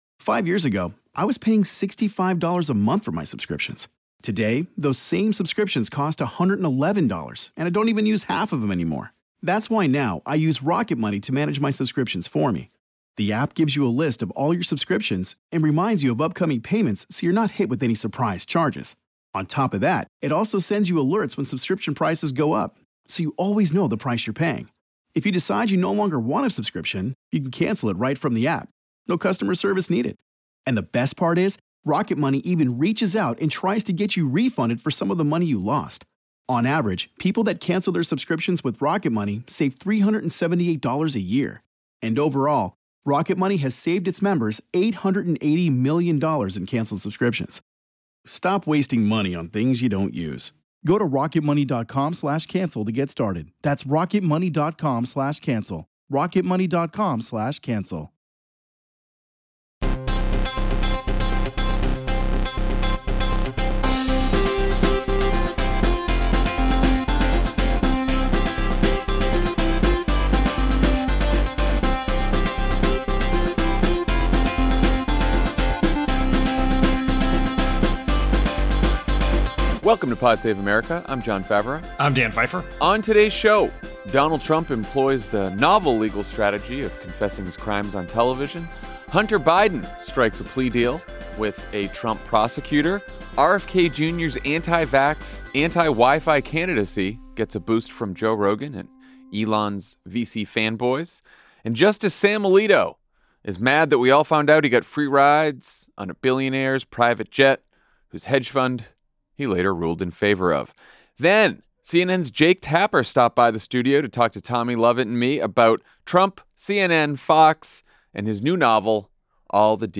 Then, CNN’s Jake Tapper stops by the studio to talk about Trump, CNN, Fox, and his new novel “All the Demons Are Here.”